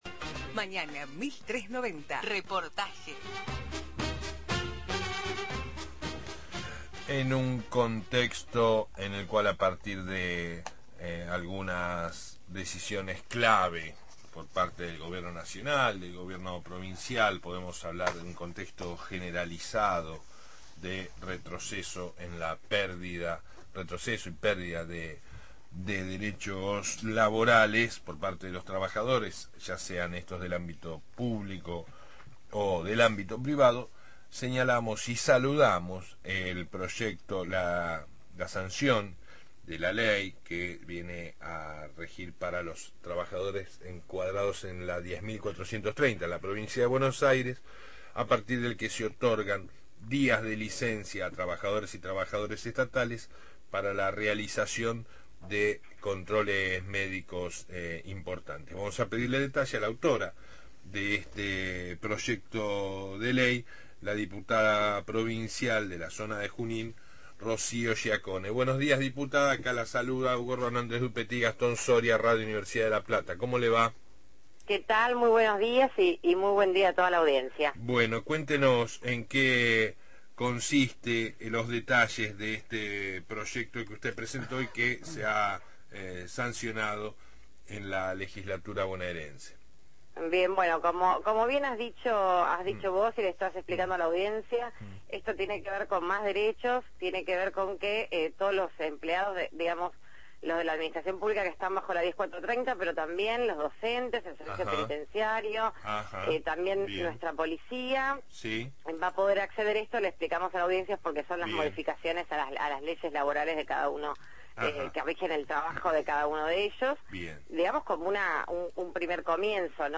Rocio Giaccone, diputada provincial por el Frente Para la Victoria, dialogó